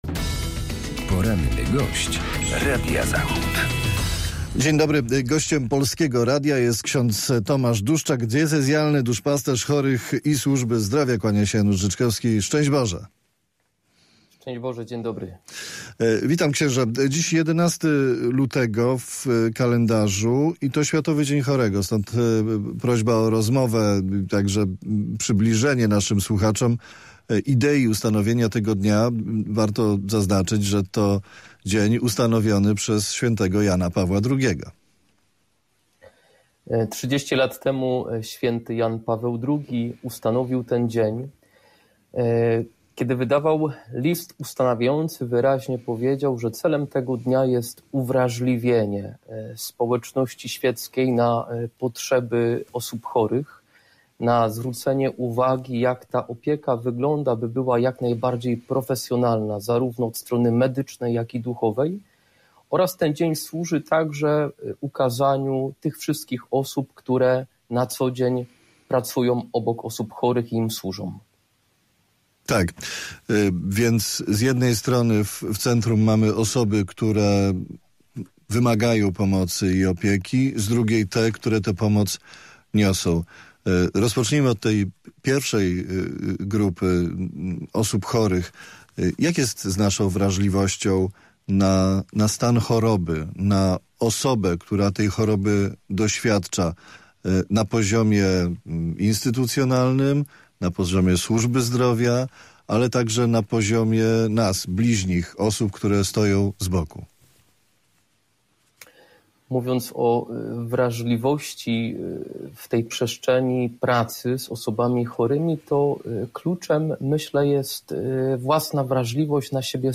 Poranny gość: